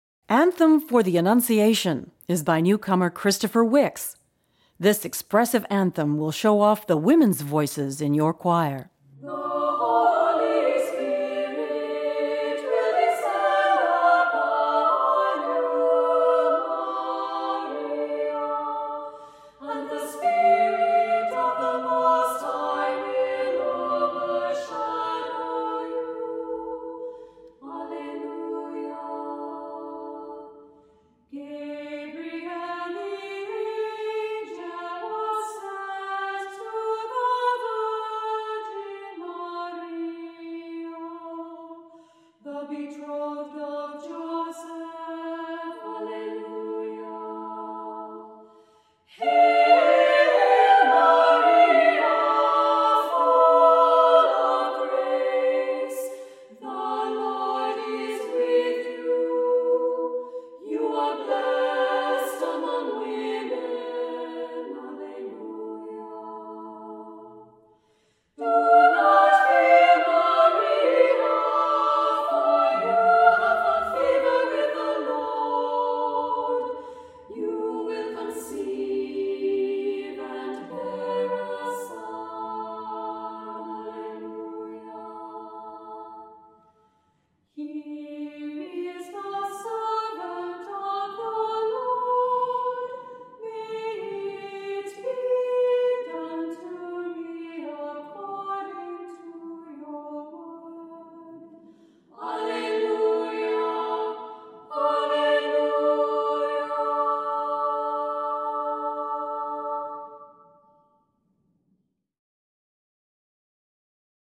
Voicing: SSA,a cappella